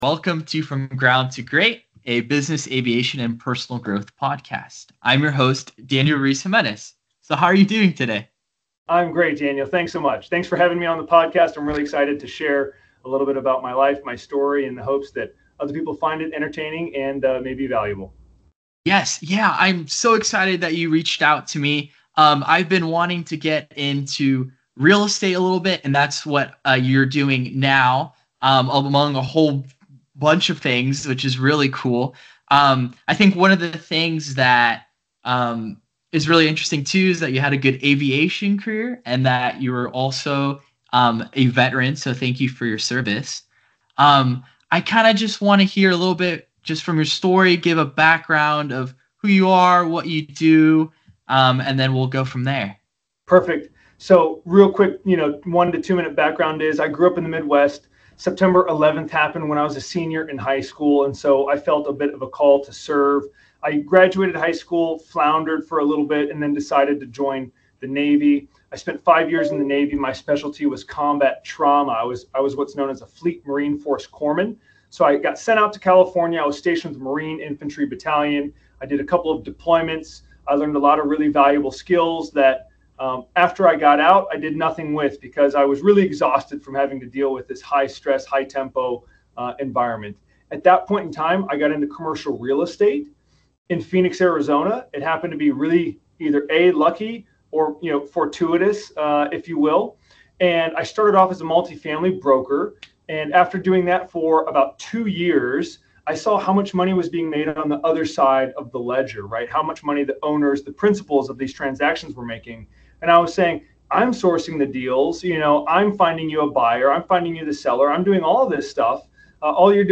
Tune in for an enlightening conversation on building a purposeful path toward personal and environmental impact.